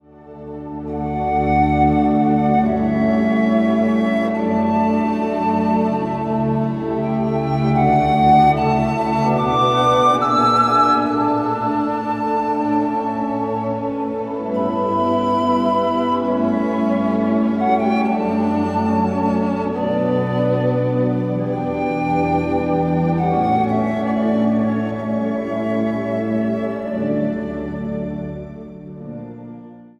Instrumentaal
Instrumentaal | Dwarsfluit
Instrumentaal | Piano